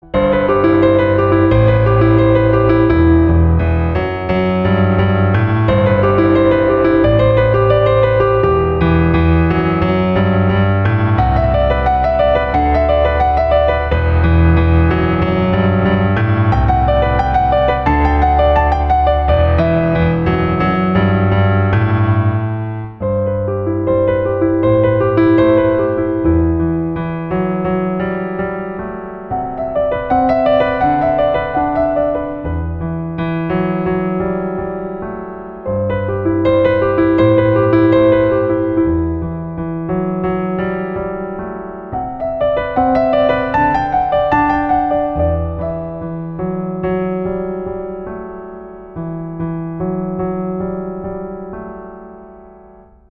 • Качество: 256, Stereo
без слов
красивая мелодия
инструментальные
пианино
тревожные
Ambient
Neoclassical
Adult contemporary